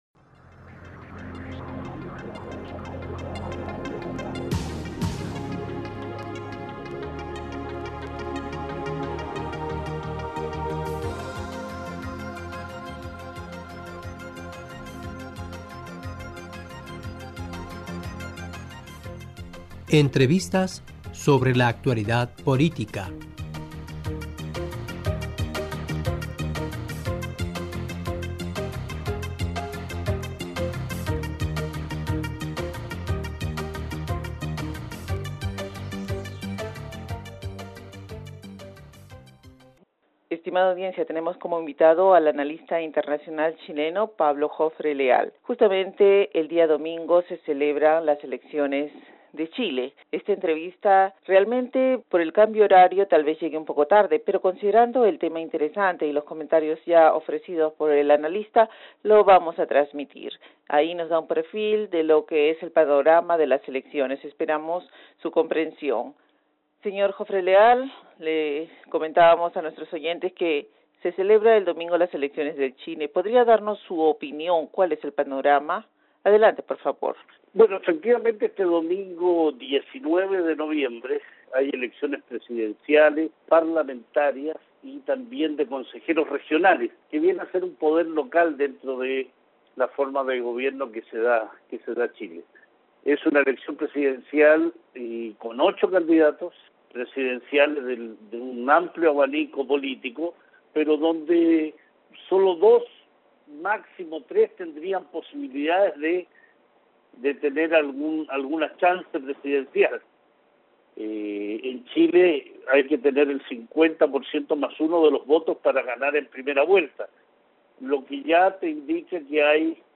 Esta entrevista, realmente, considerando el cambio horario tal vez llegue un poco tarde, pero considerando el tema importante y los comentarios vertidos por el analista la vamos a transmitir, ahí nos da un perfil de las elecciones, esperamos su comprensión.